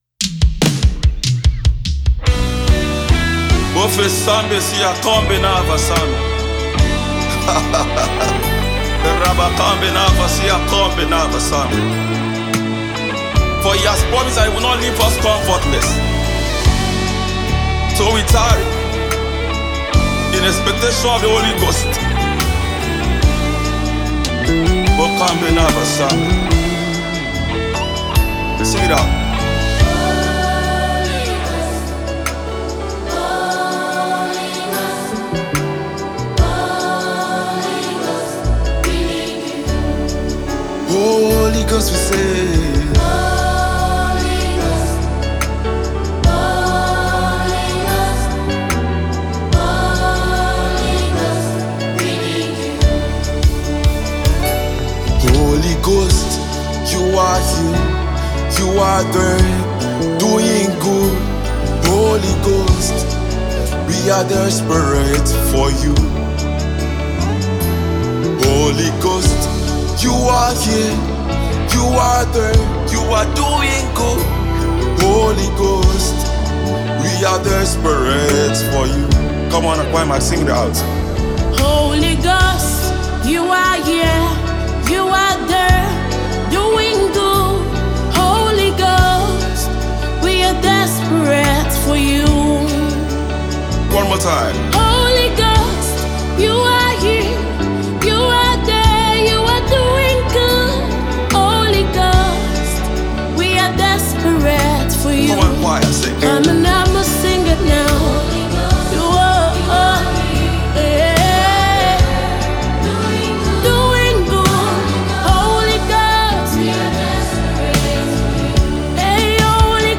is an Interdenominational gospel music family based in Uyo